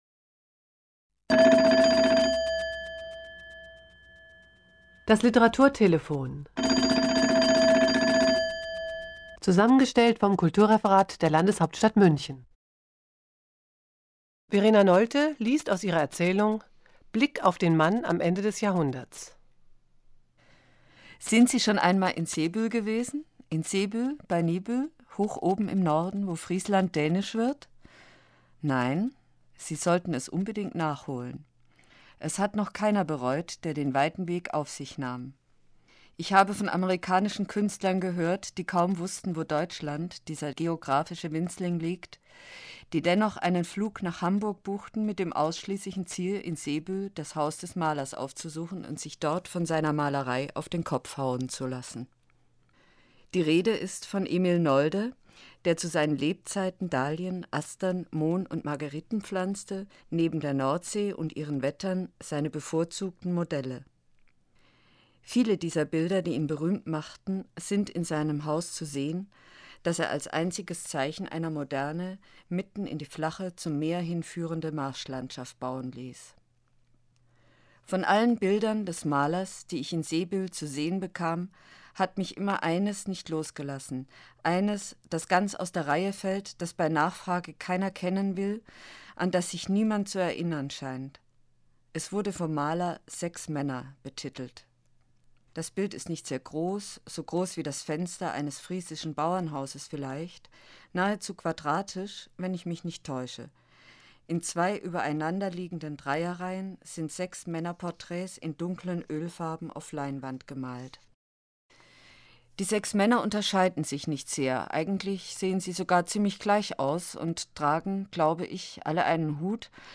Das Literaturtelefon-Archiv wird in der Monacensia im Hildebrandhaus aufbewahrt.
Die Monacensia und das Literaturportal Bayern präsentieren monatlich eine Auswahl dieser Lesungen.